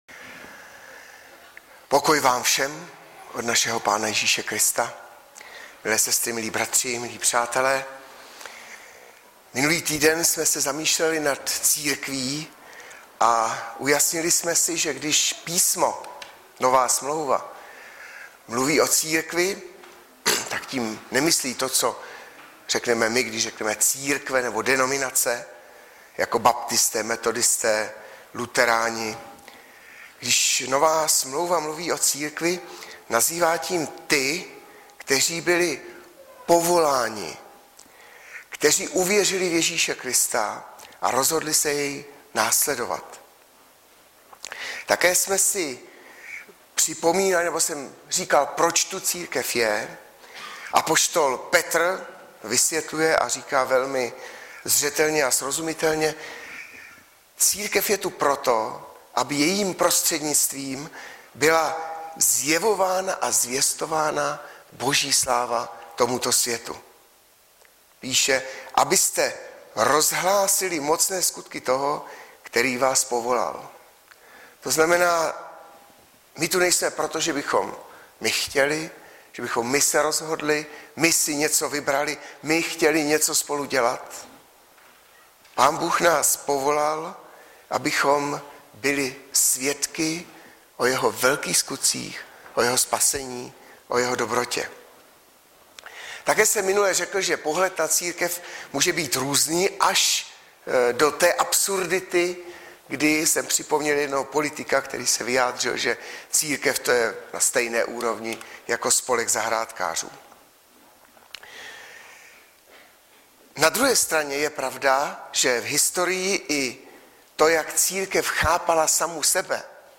Webové stránky Sboru Bratrské jednoty v Litoměřicích.
Hlavní nabídka Kázání Chvály Kalendář Knihovna Kontakt Pro přihlášené O nás Partneři Zpravodaj Přihlásit se Zavřít Jméno Heslo Pamatuj si mě  21.06.2015 - církev II.